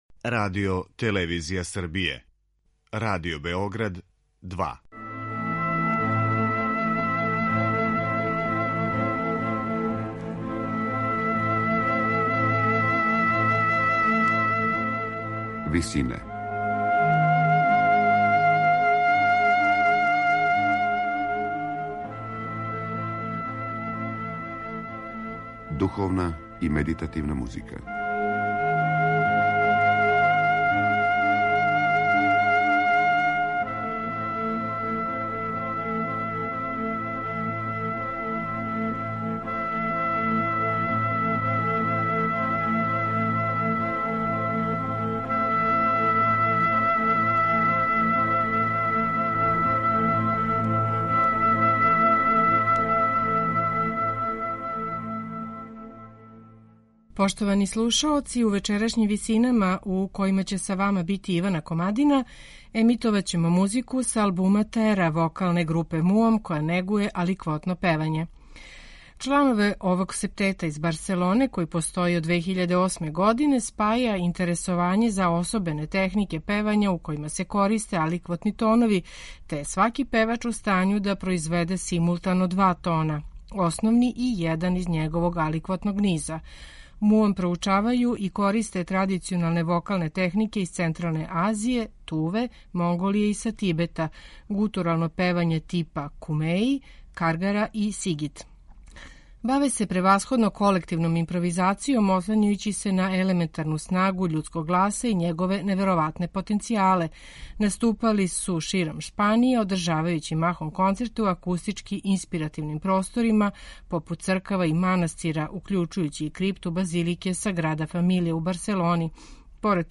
вокалног септета
гутурално певање типа кумеи, каргара и сигит.
World music